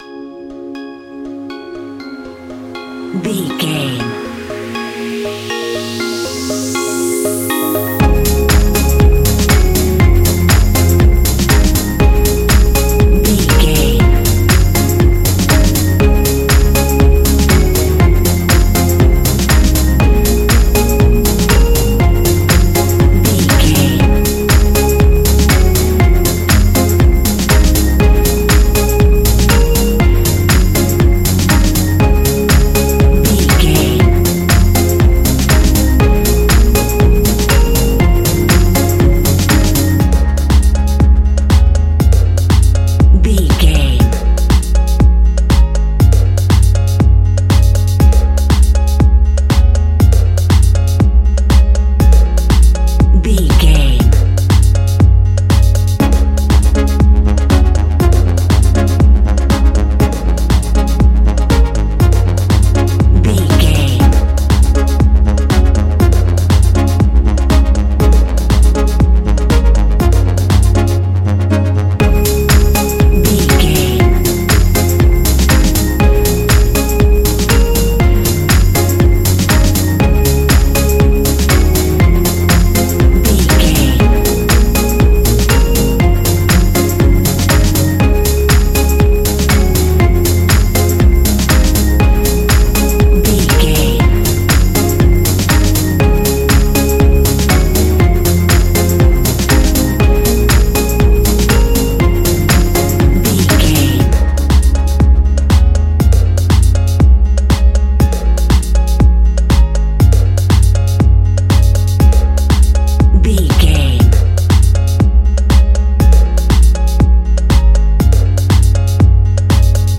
Ionian/Major
C♭
house
electro dance
synths
techno
trance